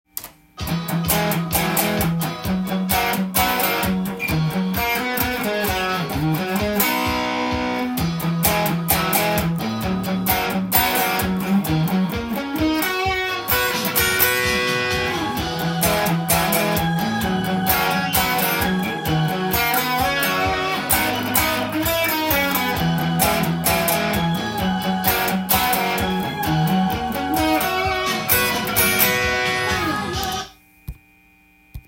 ギターアレンジしたtab譜
音源にあわせて譜面通り弾いてみました
Emajor7 と　F#　Dm7　G♯ｍになるので
keyがBとなります。